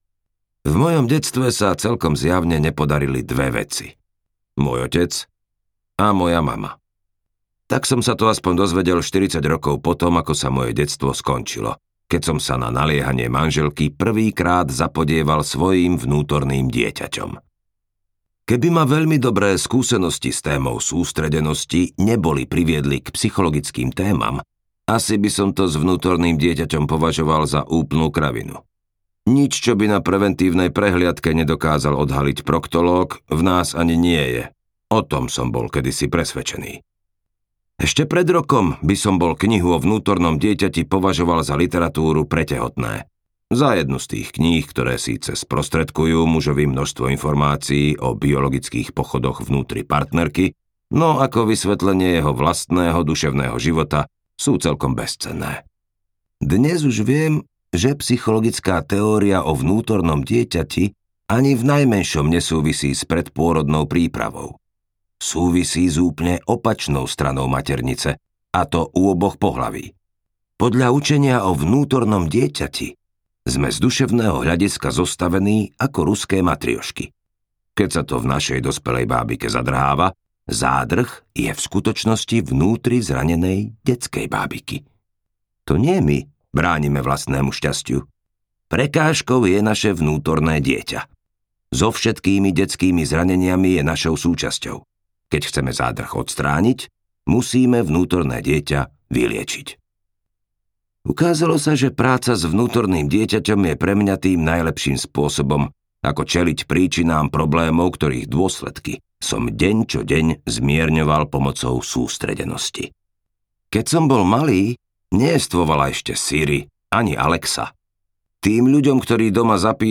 Moje vražedné vnútorné dieťa audiokniha
Ukázka z knihy